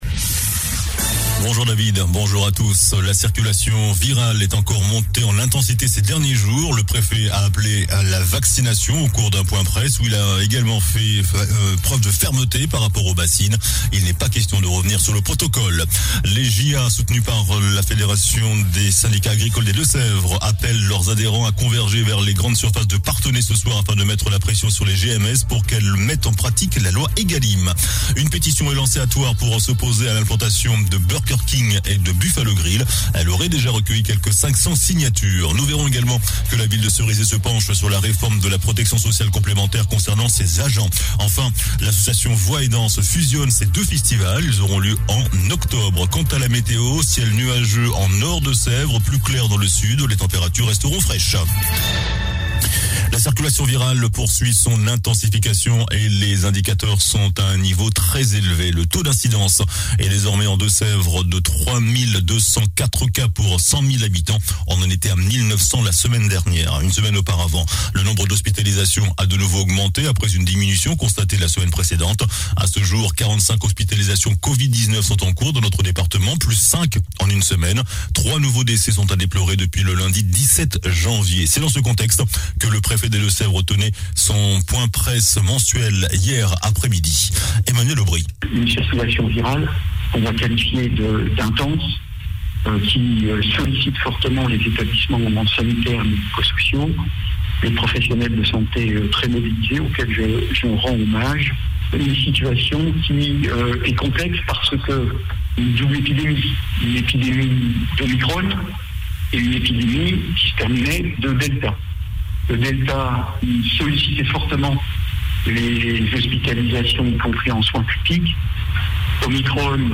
JOURNAL DU MERCREDI 26 JANVIER ( MIDI )